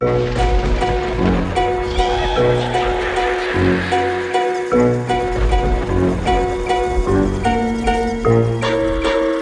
music-loop-1.wav